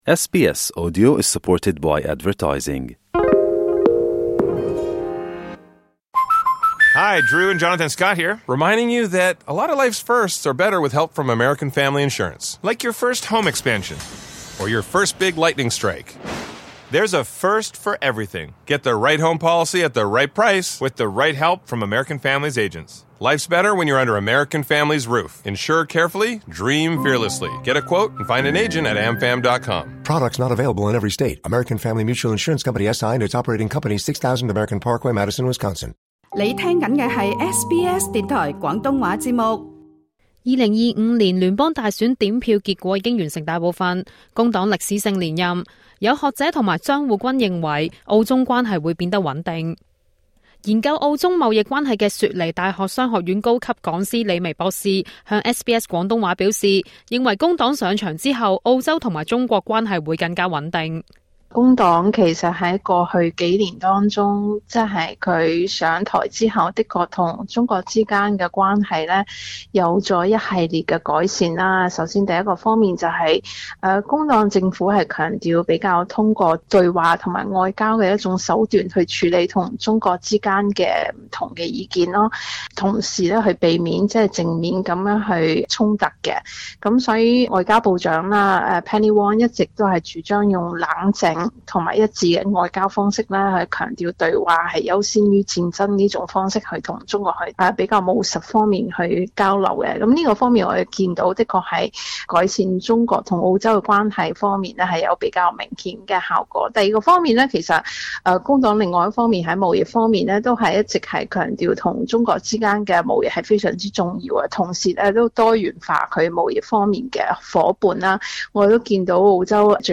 詳情請收聽錄音訪問。